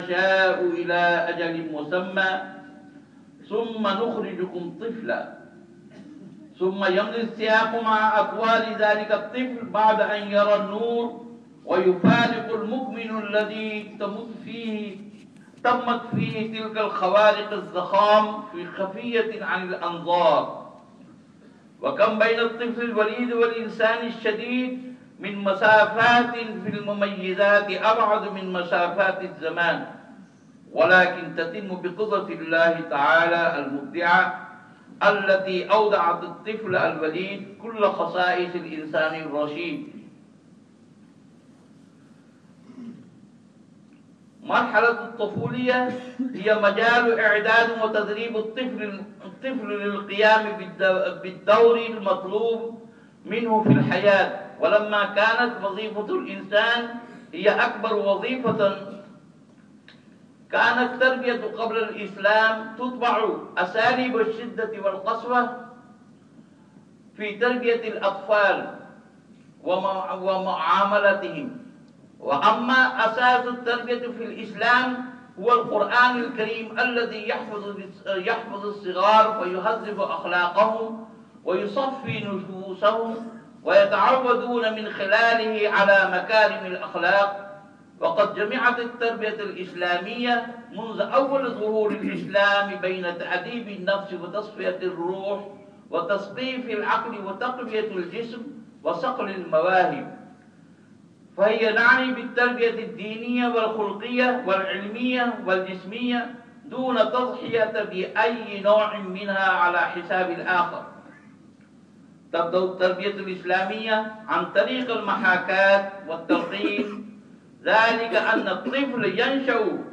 Friday Sermon